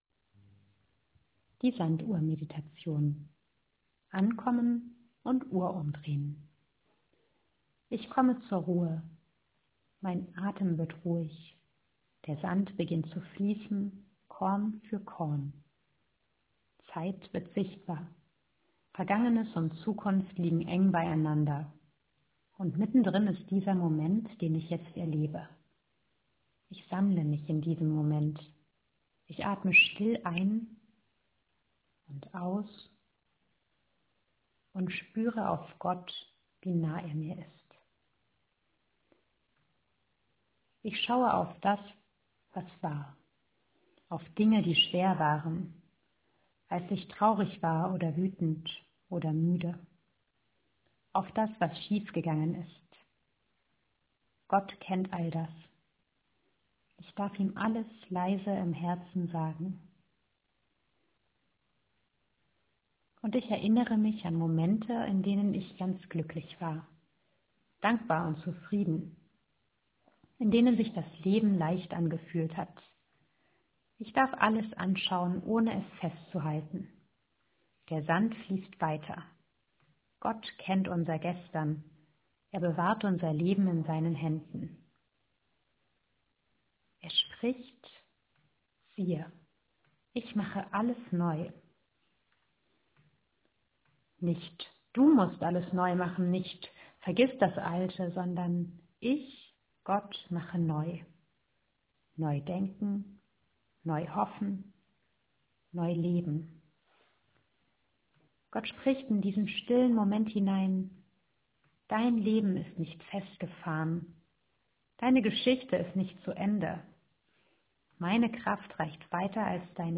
Predigt vom Sonntag
Sanduhr-Meditation-.m4a